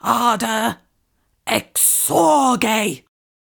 mandrake fvttdata/Data/modules/psfx/library/incantations/older-female/fire-spells/ardor-exsurge
ardor-exsurge-slow.ogg